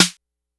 Snares
snr_08.wav